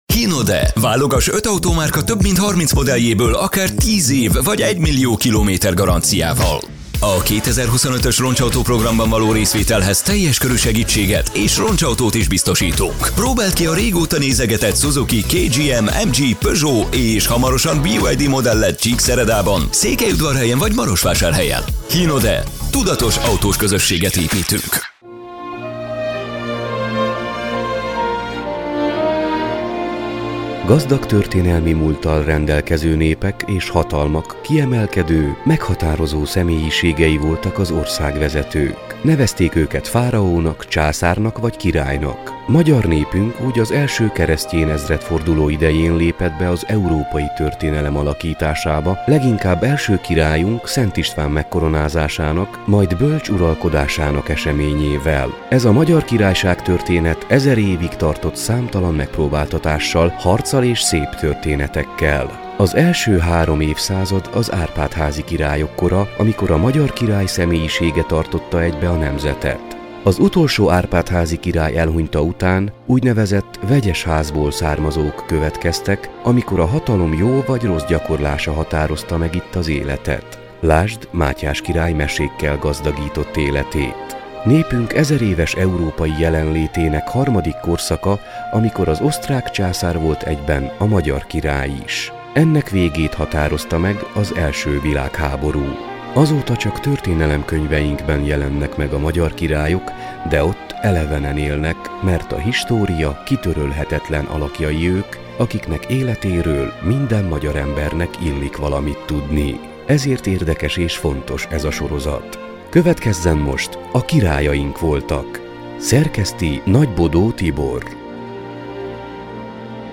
beszélget.